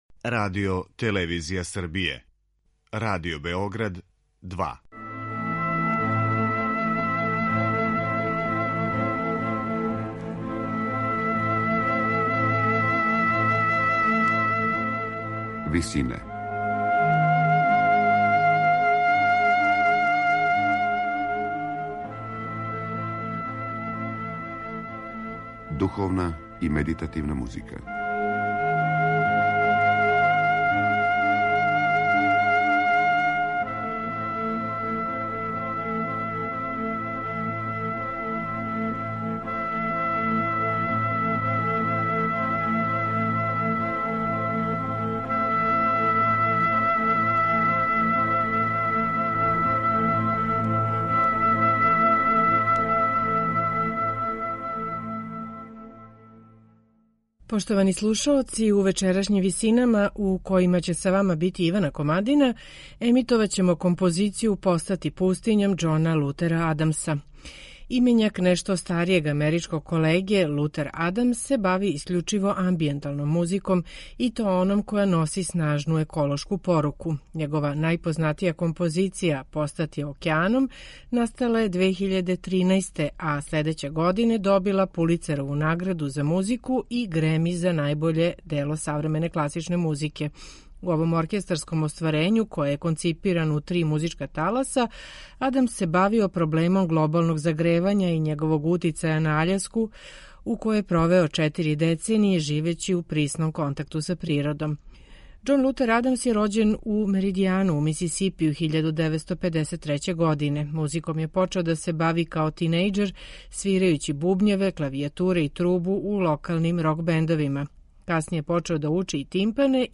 бави се искључиво амбијенталном музиком
за симфонијски оркестар